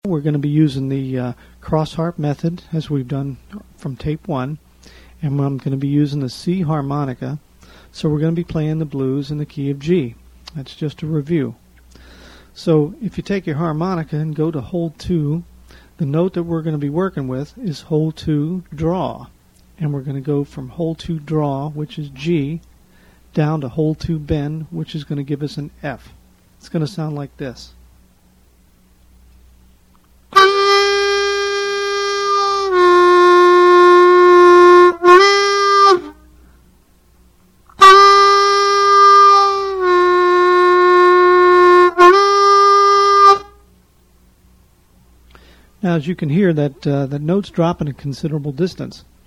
#2 is my Learn How to Bend Notes tape. I have given all of the tricks and tips to help you bend notes that I have picked up in my years of teaching. This tape also includes an introduction to the blues scale, a number of different guitar jam tracks for practice, and tips on improvising and building solos. This tape is also done in the Key of G, so you can use your trusty C harp with it.
They also include guitar rhythm tracks so you can practice the material on each CD and tape with a rhythm background.